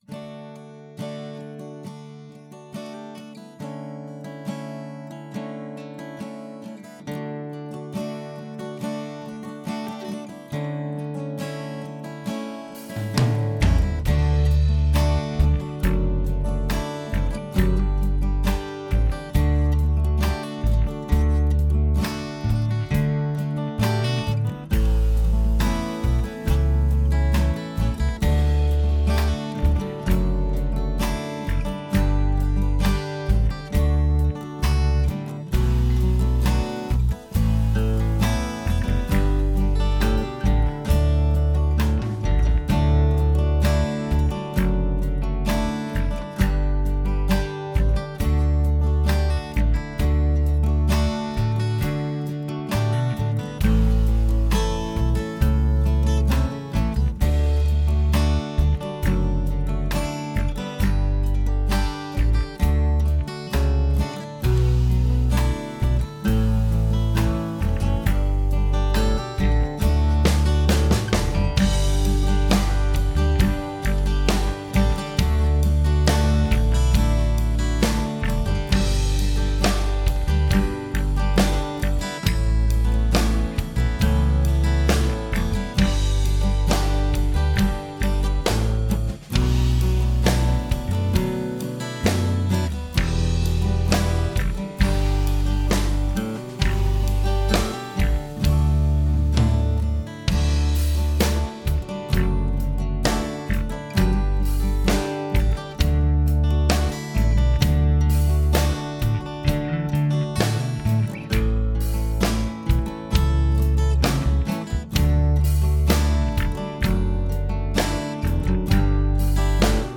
Yamaha FG-411 CE Acoustic